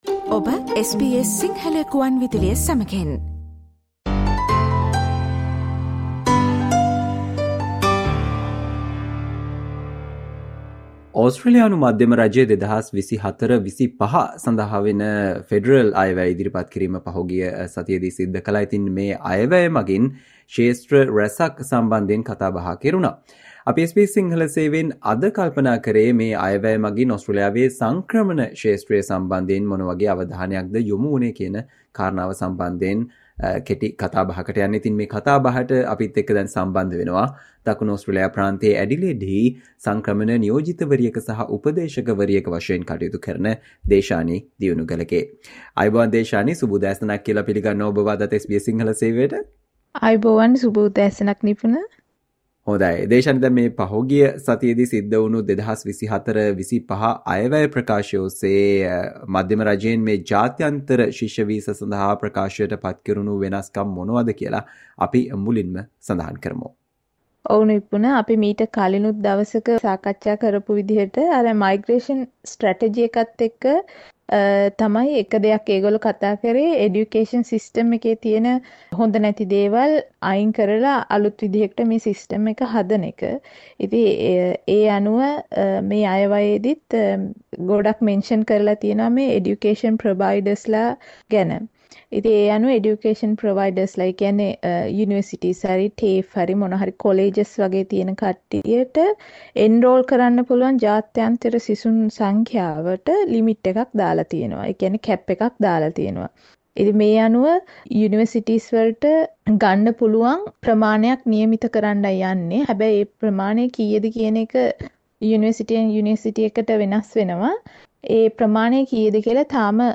SBS Sinhala discussion on the things that have affected International student visas from 2024 Federal Budget